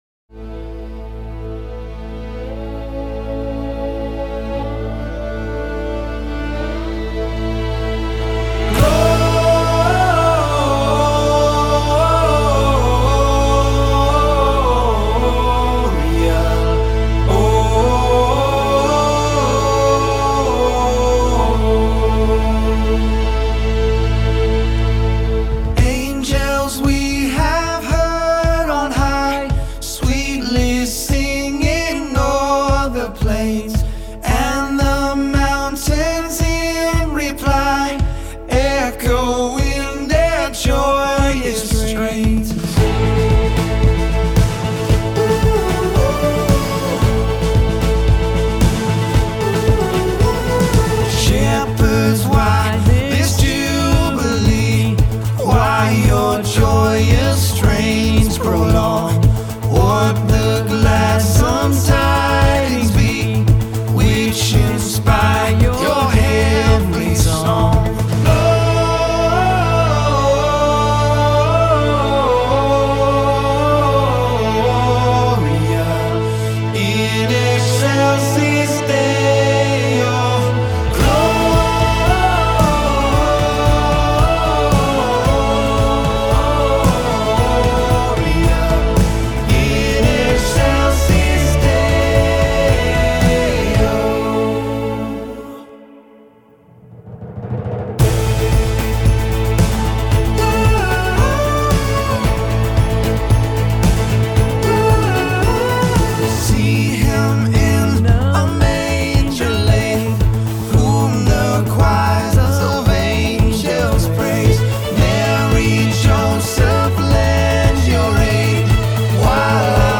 Christmas Choir - All Parts
Practice Track - Tenor Only
Angels We-For King-Tenor.mp3